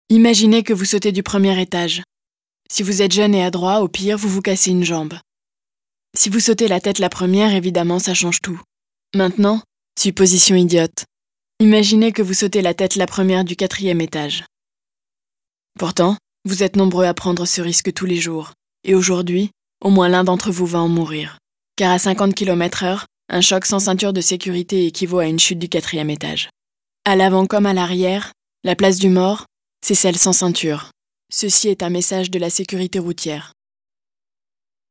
Voix reconnaissable typique modulable jeune posée dynamique
Sprechprobe: eLearning (Muttersprache):